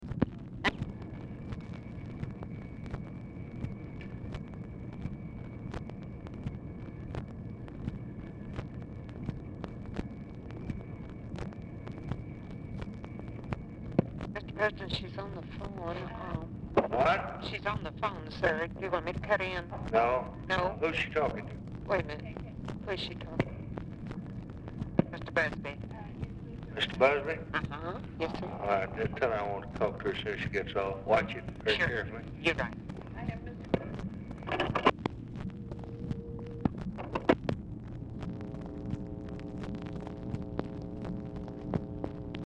Telephone conversation # 4655, sound recording, LBJ and TELEPHONE OPERATOR, 8/3/1964, time unknown | Discover LBJ
Format Dictation belt
Location Of Speaker 1 Oval Office or unknown location